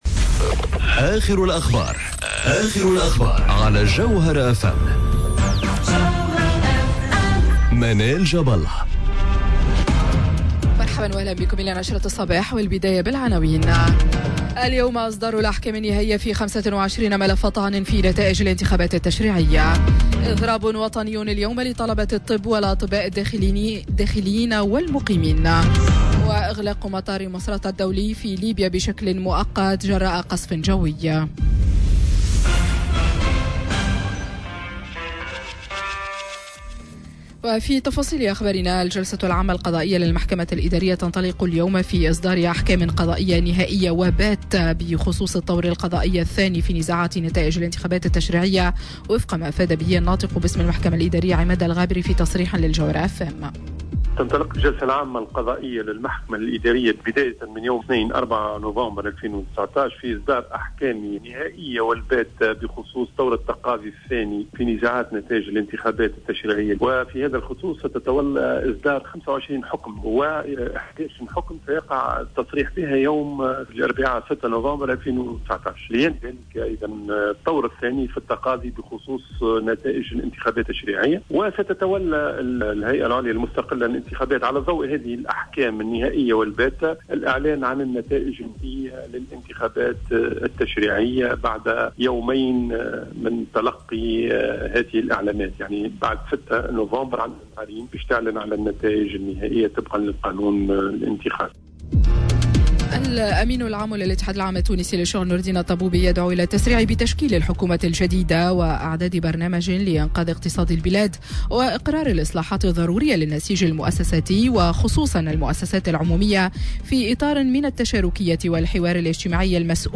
نشرة أخبار السابعة صباحا ليوم الإثنين 04 نوفمبر 2019